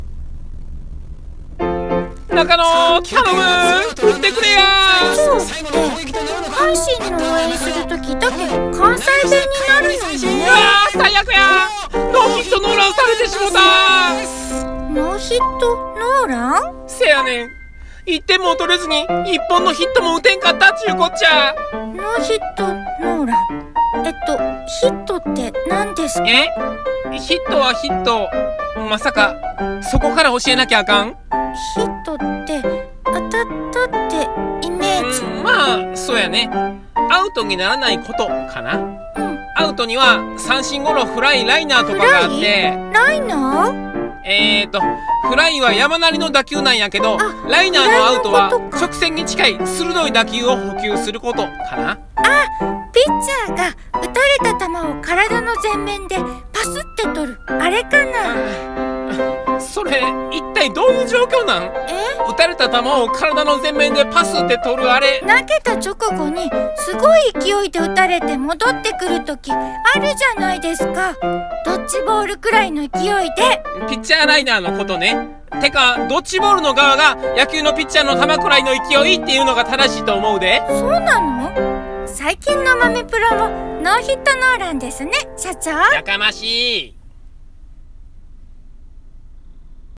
声劇「豆プロ野球教室🐯」